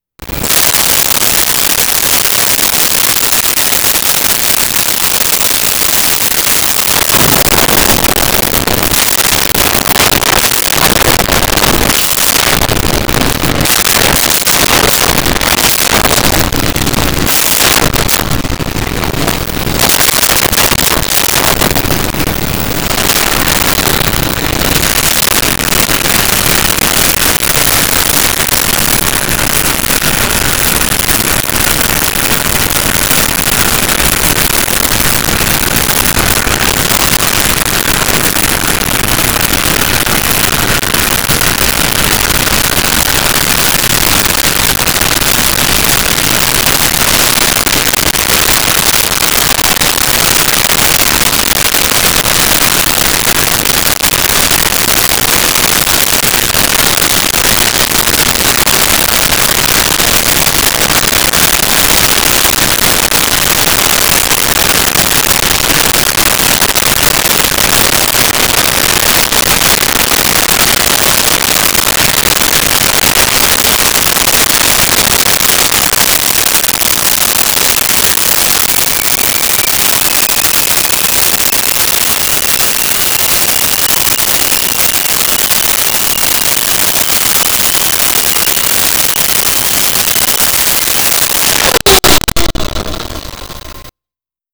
Kettle Boiling Sequence
Kettle Boiling Sequence.wav